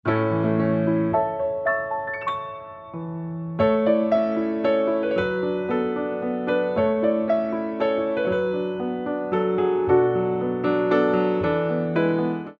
29 Original Piano Pieces for Ballet Class
Révérence
mod. 3/4 - 0:34